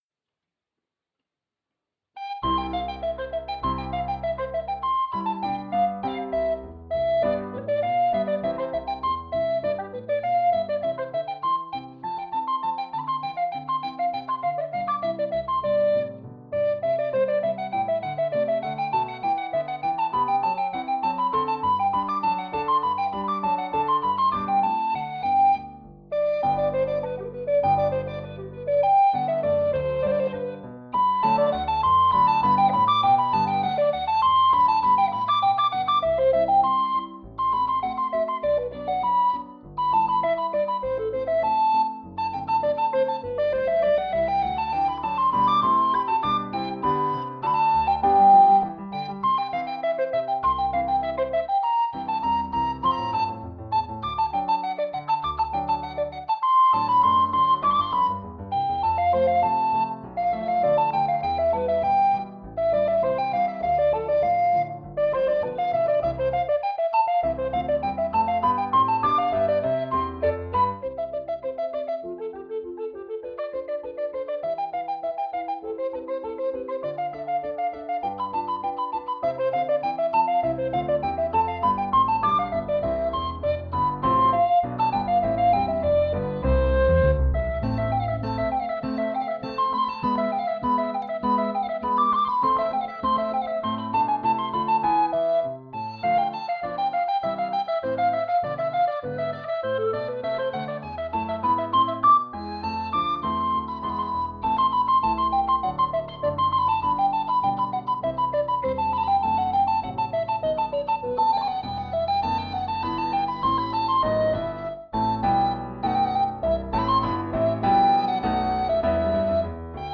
Allegro by Joseph Hector Fiocco- Arranged for alto recorder
Hi, I love this piece so much but I don't have a violin to play it, so I decided to arrange it for alto recorder.
Hope you like it. i recorded the recorder alone and then added it together with the acompaniment.